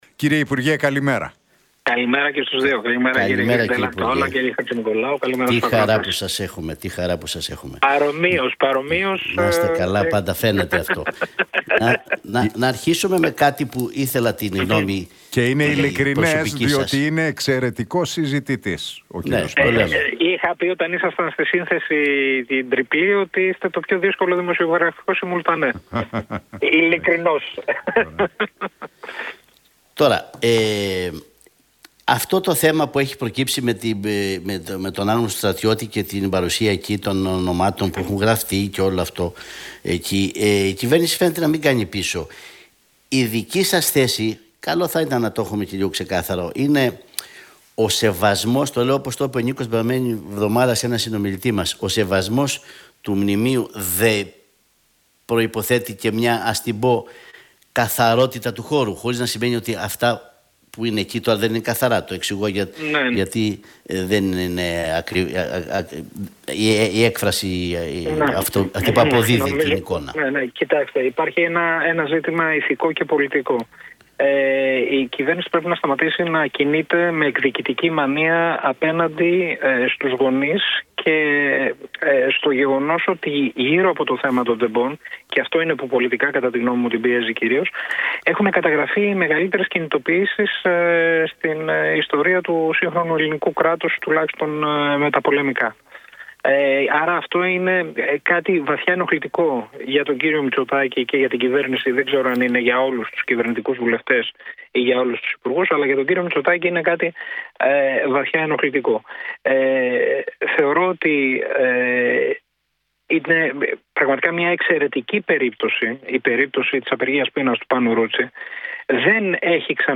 Εκπρόσωπος του ΣΥΡΙΖΑ, Νίκος Παππάς.